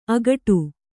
♪ agaṭu